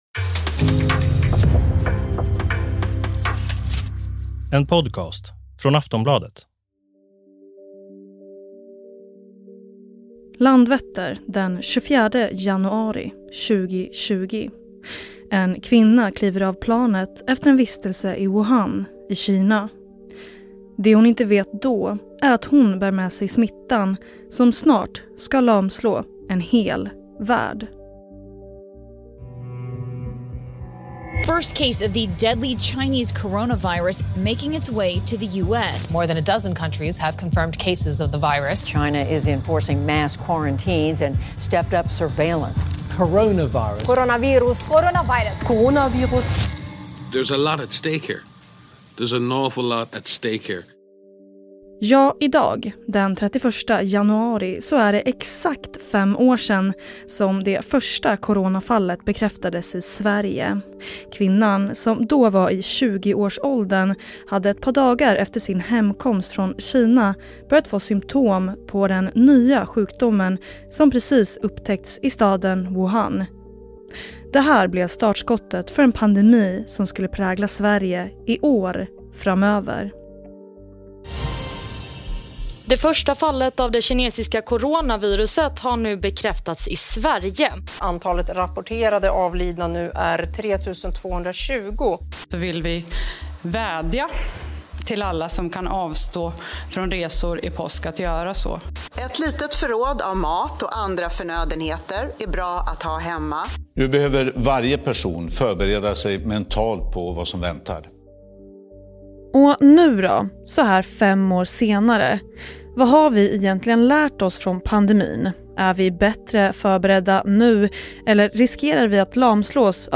Klipp i avsnittet: SVT Dokumentären När covid stannade världen, Aftonbladet, Tv4 Nyheterna, SVT.